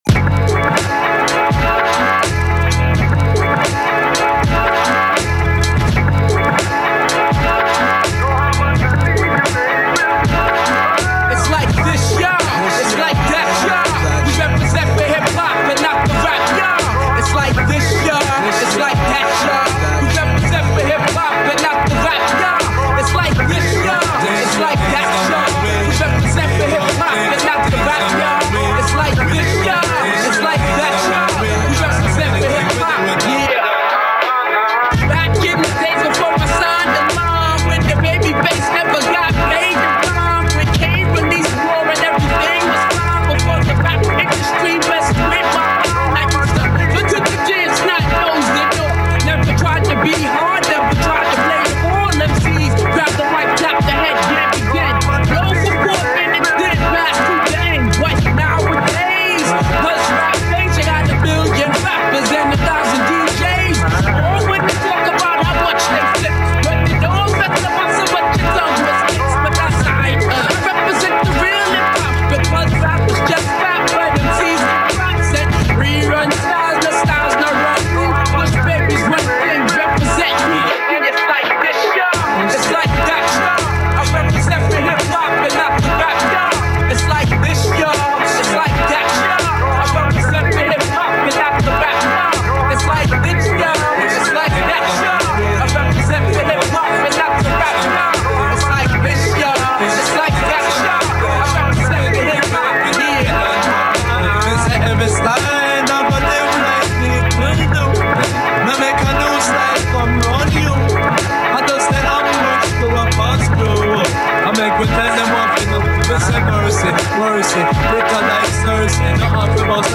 Your tracks are heavy.
Yeah I’ll probably not do anything else with them but maybe :slight_smile: The second one was trying out the new looper on the 404 so limited what I can do with that one!